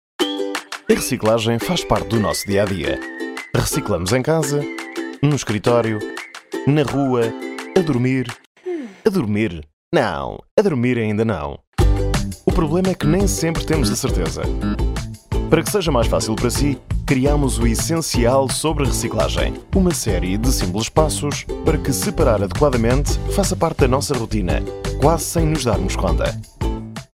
Commerciale, Naturelle, Polyvalente, Fiable, Amicale
E-learning
Calm, deep recording, aggressive sales voice, lively, friendly, conversational.